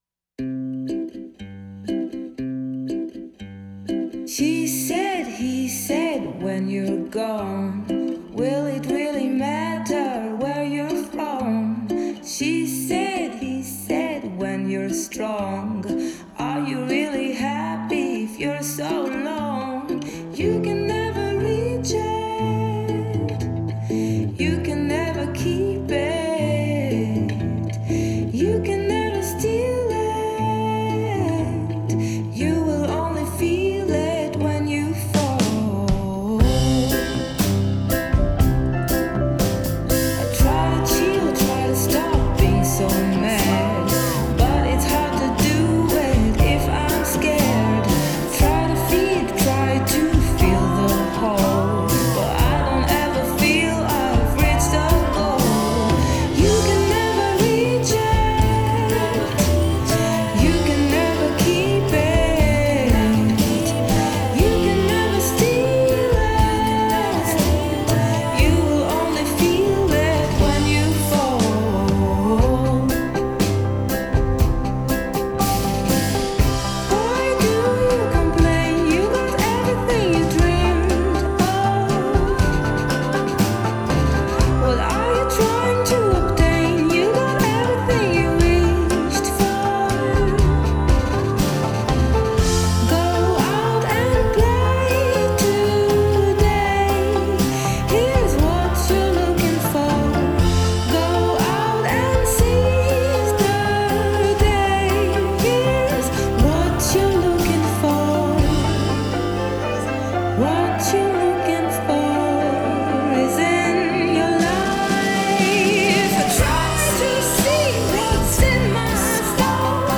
Жанр: Indie, Folk, Pop
Genre: Female vocalists, Indie, Folk, Pop